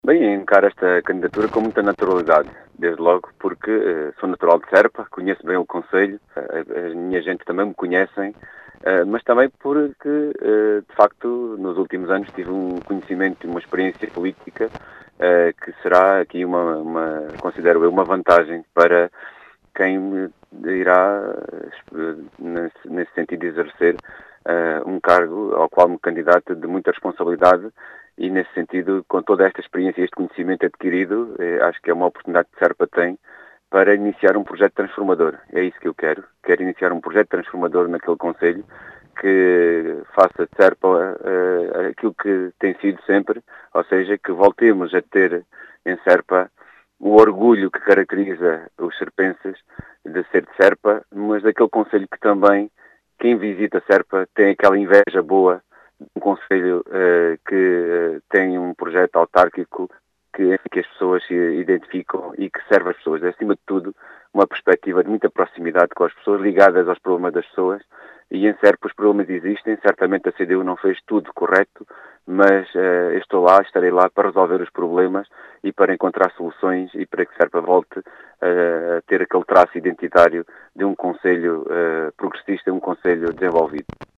Em declarações à Rádio Vidigueira, João Dias, que vê com “naturalidade” esta candidatura,  evoca a sua “experiência política” para iniciar um “projeto transformador”.